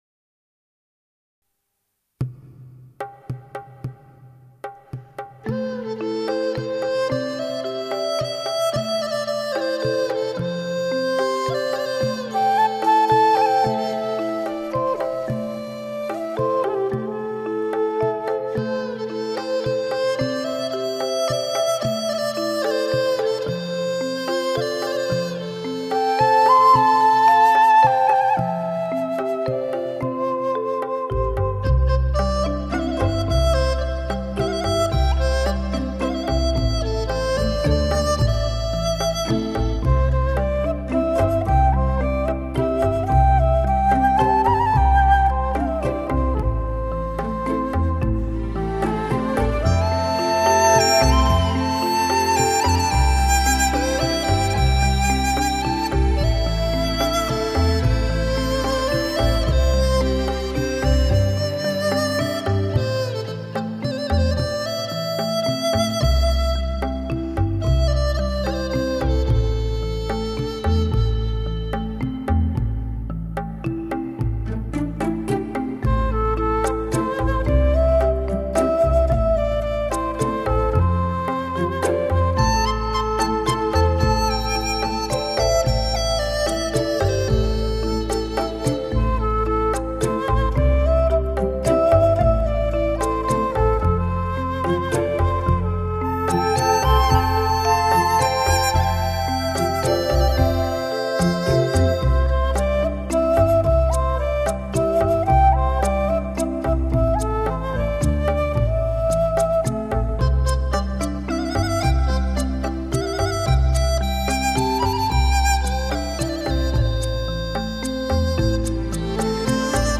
音乐类型: 流行音乐/Pop